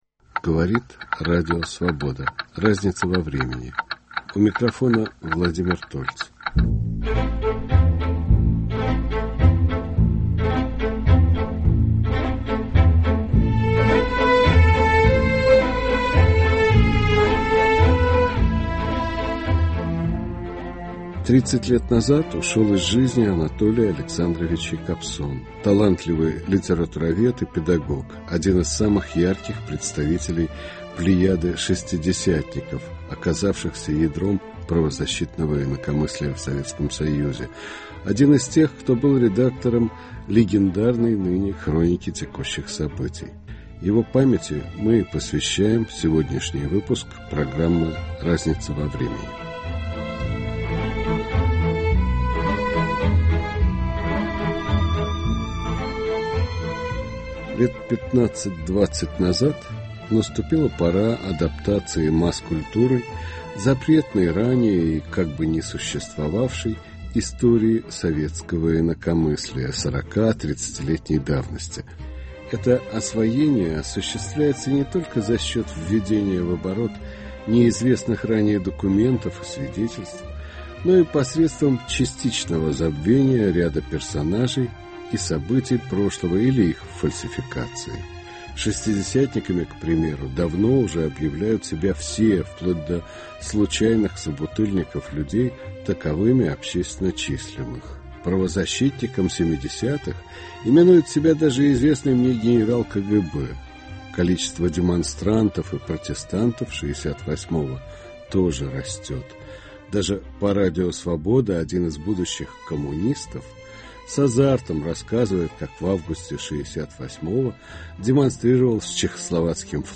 Участники - ветераны советского правозащитного движения Елена Боннэр